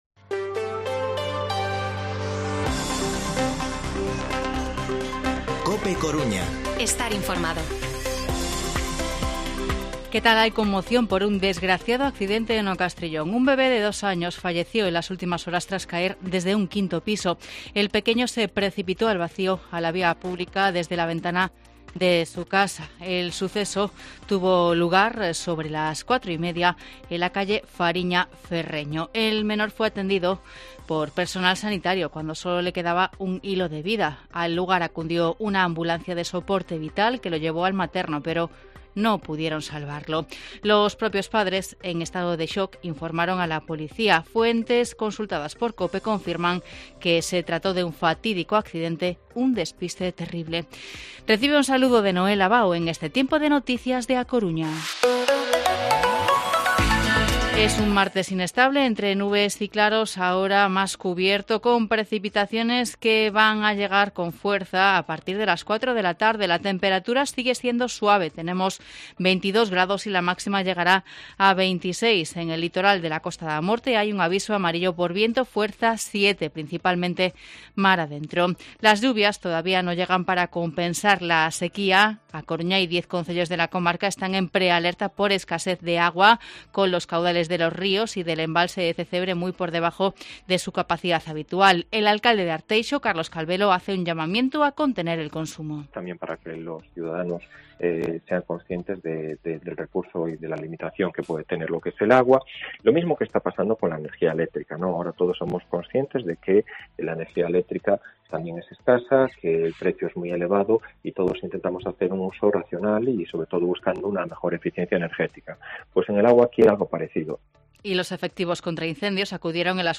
Informativo Mediodía COPE Coruña martes, 13 de septiembre de 2022 14:20-14:30